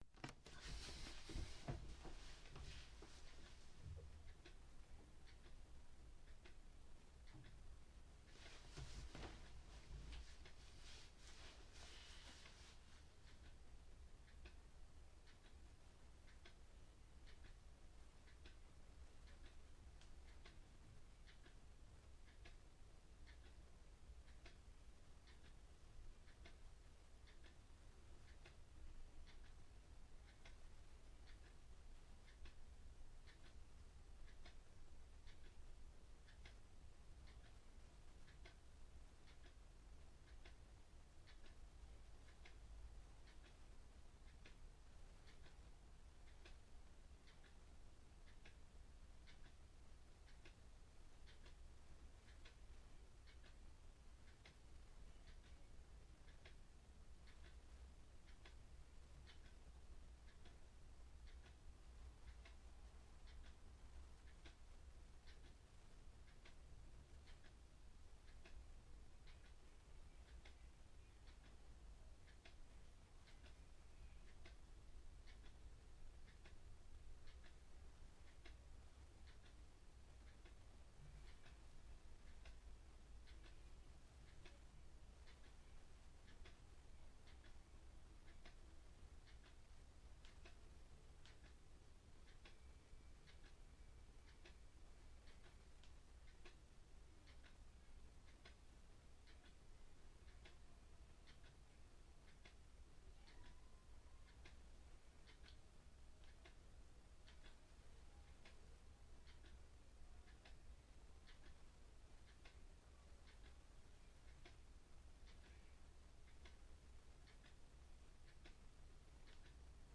描述：弗雷泽镜头电机发动机控制单元
标签： 单元 电机 透镜 弗雷泽 控制发动机
声道立体声